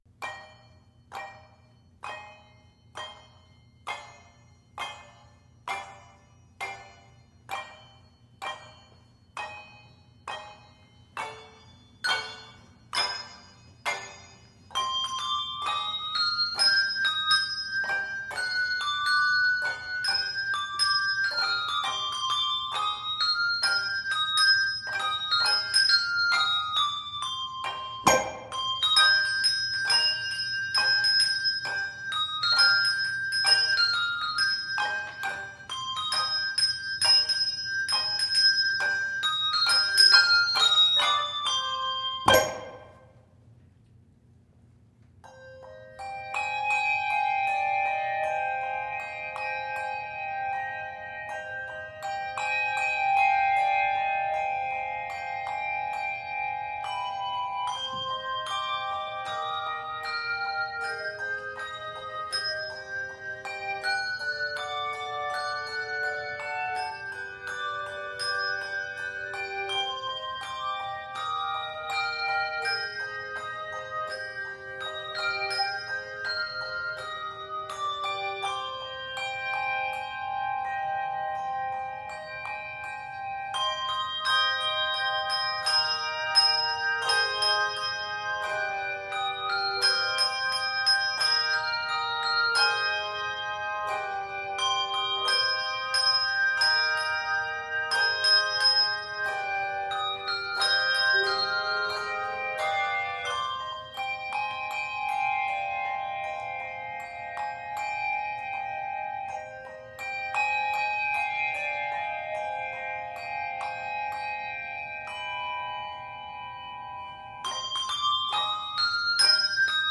this arrangement is scored in c minor.